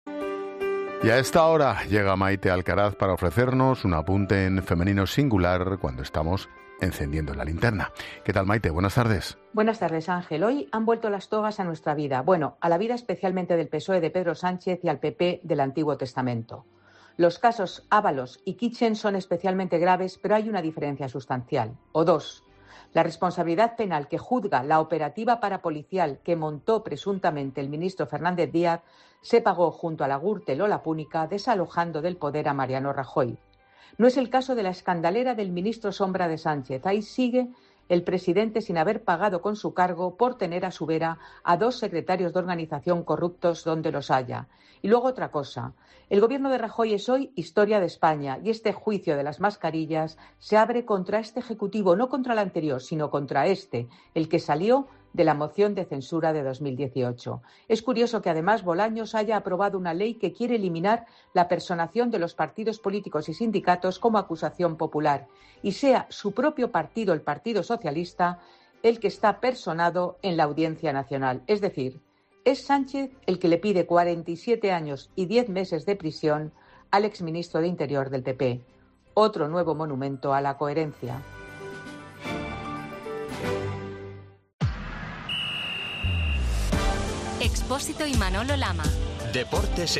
La periodista desgrana en 'La Linterna' las diferencias sustanciales en la asunción de responsabilidades políticas entre el caso de las mascarillas y la trama del PP